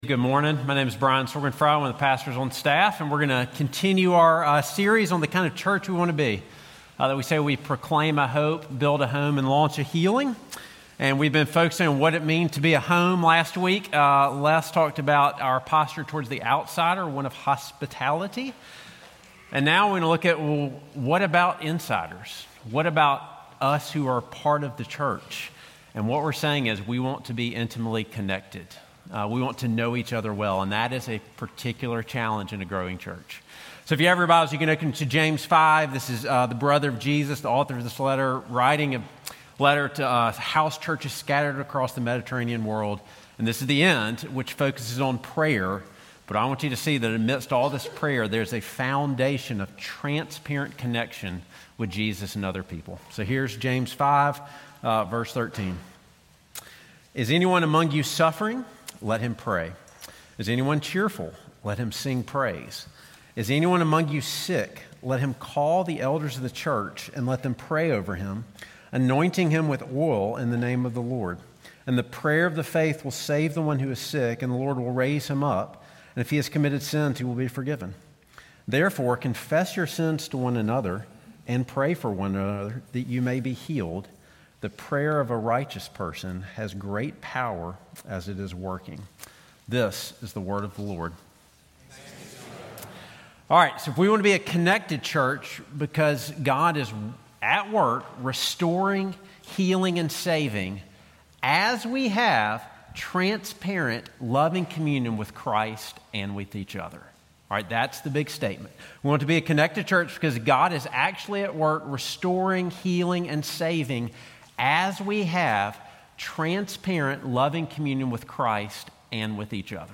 James 5:13-16 If we are going to be a church that is a home, that entails being in transparent, intimate relationships with both Jesus and each other. These kind of life-giving relationships require admitting the truth about our messy selves, but a promise of healing and restoration is on the other side. Sermon